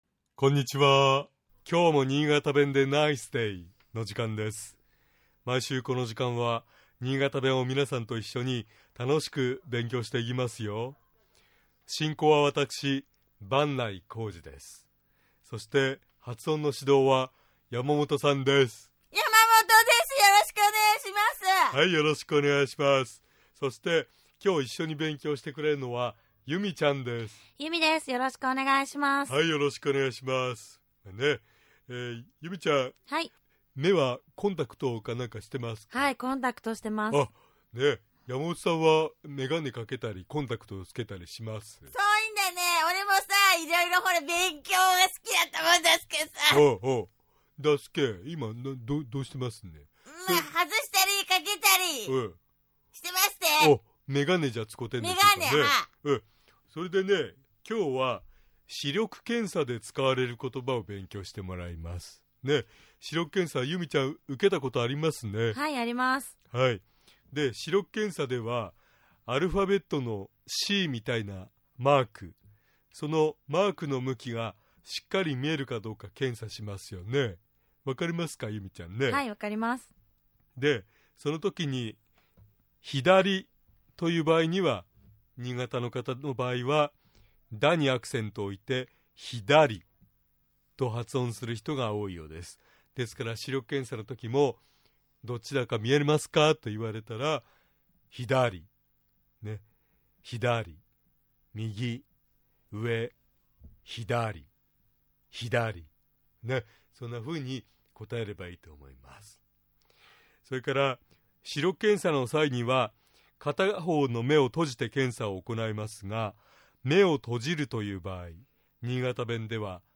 今日は、視力検査で使われる言葉について勉強しましょう。 まず、視力検査では、 アルファベットの「Ｃ」のようなマークの向きがしっかり見えるかを検査しますが、 「左」と言う場合、 新潟では「だ」にアクセントを置いて頭高で発音する人が多いようです。